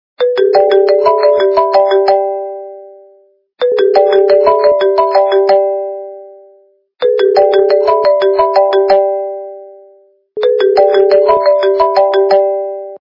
При прослушивании Звонок для СМС - IP Phone качество понижено и присутствуют гудки.
Звук Звонок для СМС - IP Phone